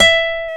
Index of /90_sSampleCDs/Roland L-CD701/GTR_Nylon String/GTR_Classical